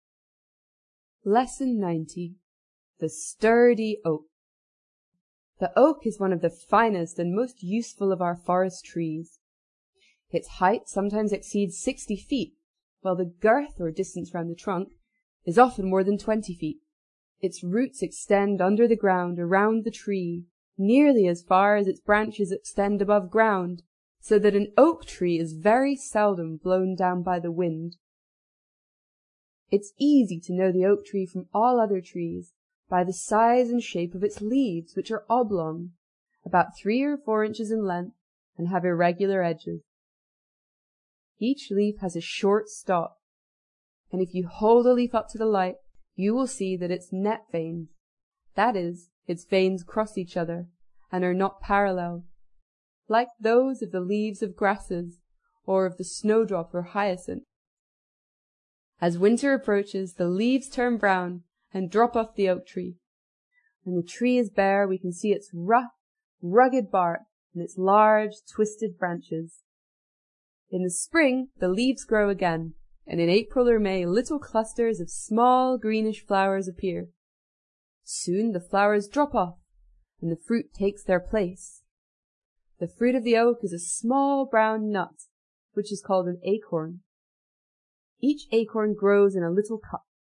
在线英语听力室英国学生科学读本 第90期:结实的橡树(1)的听力文件下载,《英国学生科学读本》讲述大自然中的动物、植物等广博的科学知识，犹如一部万物简史。在线英语听力室提供配套英文朗读与双语字幕，帮助读者全面提升英语阅读水平。